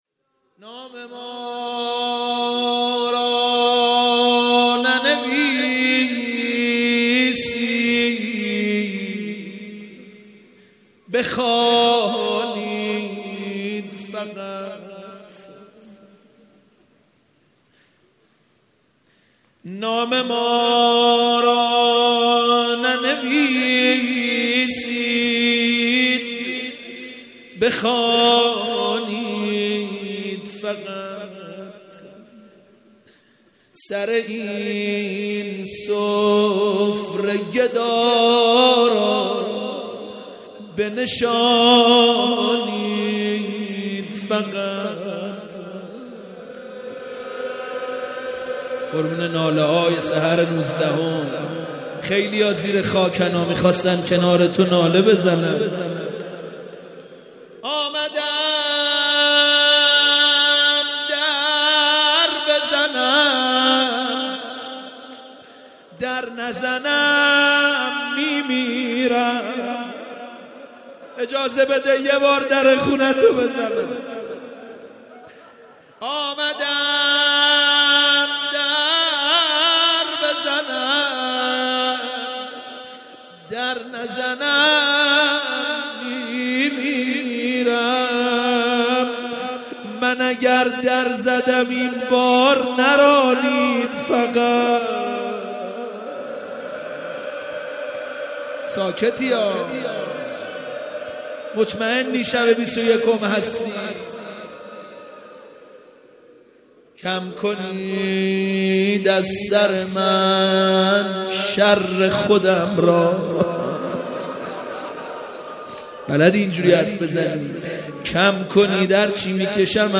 شور عبد الرضا هلالی